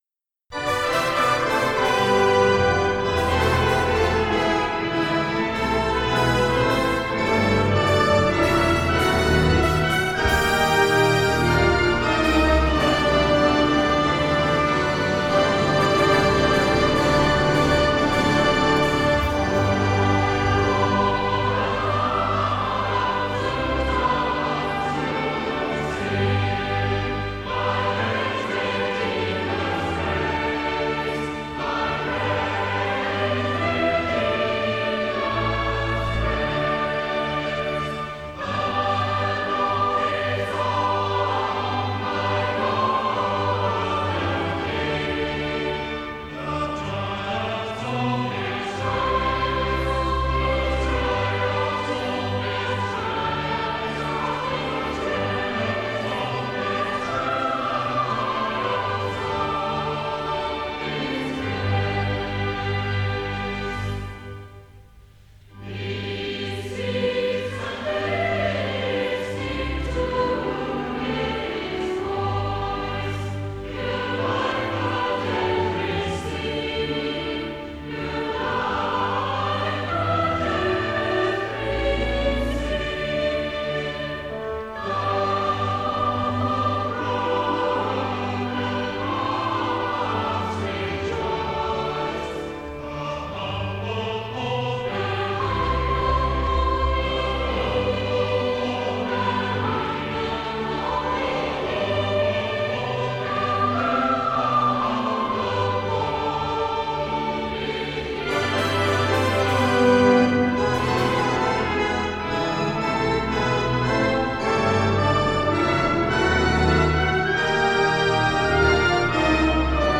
From time time to time I would ask one of kind PA men to record the music in the service on my trusty Trio Cassette Deck.
This was an additional strain on the singers and instrumentalists who were all sat at the front and had to maintain the appearance of being awake.
Perhaps they were singing and playing with such gusto because they had survived. The hymn is very long and there were a few minor accidents in some verses which have been edited out.